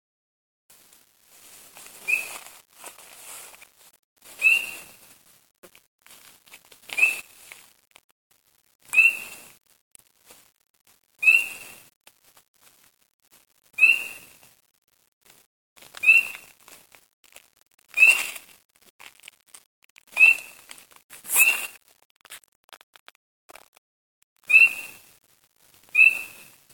Spring Peeper 01
Recorded at the Webb Wildlife Center.